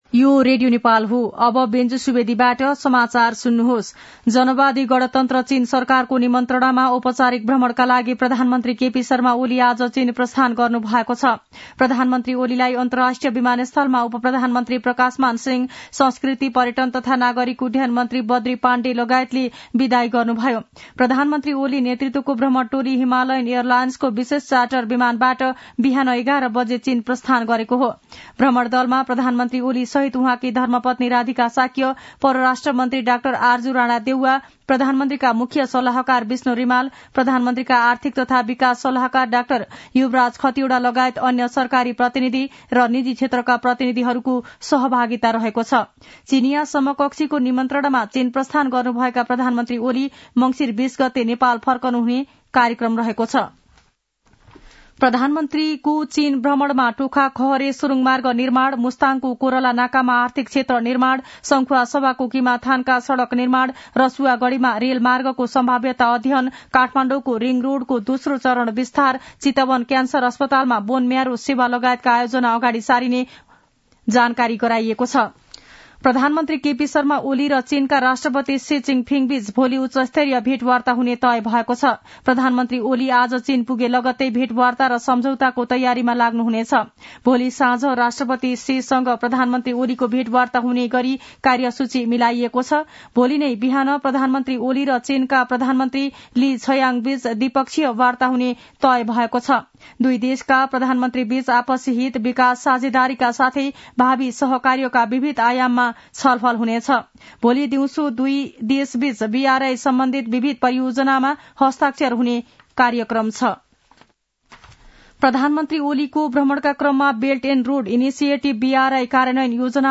मध्यान्ह १२ बजेको नेपाली समाचार : १८ मंसिर , २०८१
12-am-nepali-news-.mp3